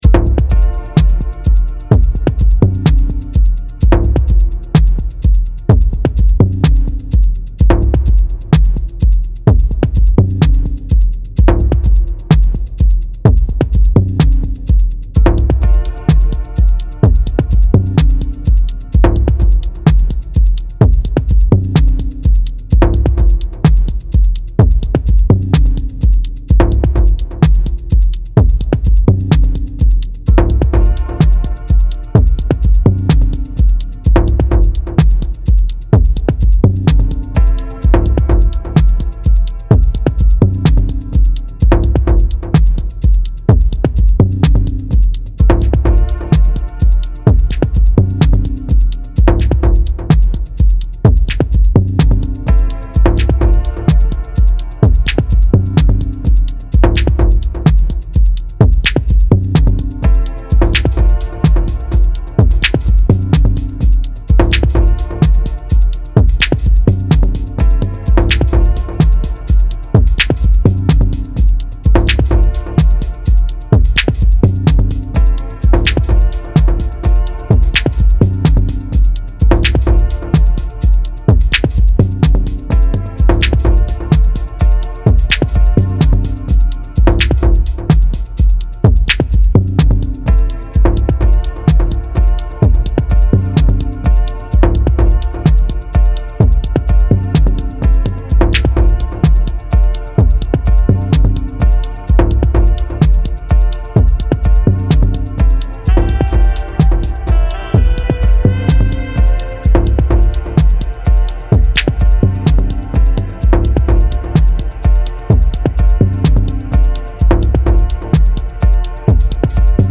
Dub Techno
Habe versucht mal etwas mehr Reggae einfließen zu lassen. Allerdings hat das Piano ein sehr einnehmenden Charakter und verändert den Vibe des Tracks, der ja eher minimalistisch sein soll.
Anhänge A Dub (reggae version).mp3 6,9 MB · Aufrufe: 139